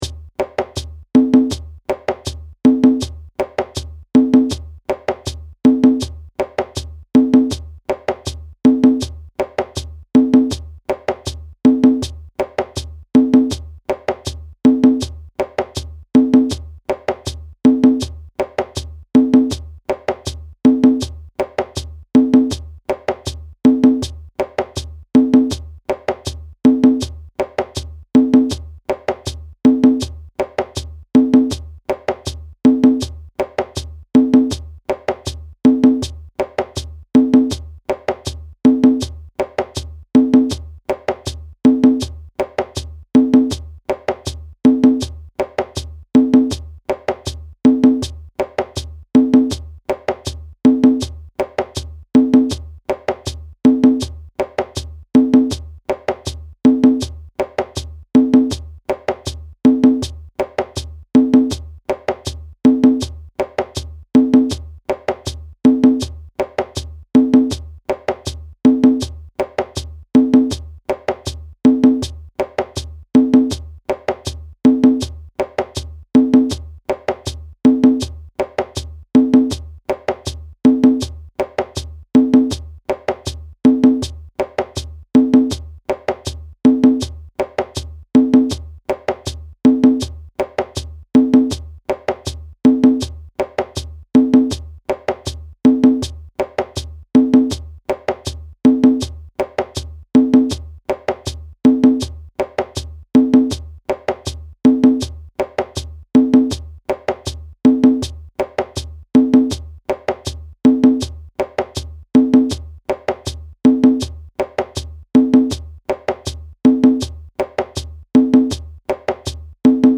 A drum circle favorite, this electric rhythm incorporates West African and Brazilian rhythm concepts (in 4/4 time).
middle part audio (with shekeré)
West-African-Samba-mid-hh.mp3